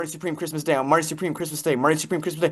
marty supreme Meme Sound Effect
This sound is perfect for adding humor, surprise, or dramatic timing to your content.